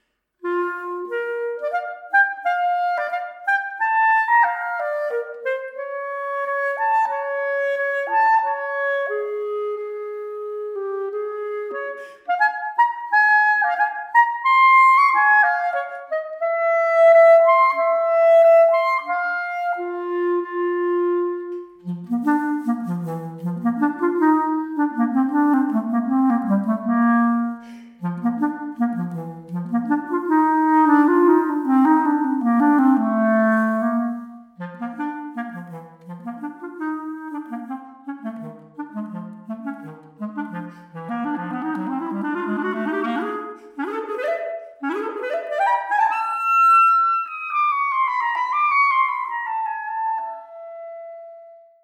Klarinette:
Im Gegensatz zur Oboe wird der Ton bei der Klarinette über ein einfaches Rohrblatt erzeugt. Auf der Aufnahme spielt sie eine Katze, die bei den tiefen Tönen herumschleicht und bei den hohen, schnellen Tönen ihrer Beute nachjagt.
Klarinette-Peter_und_der_Wolf.mp3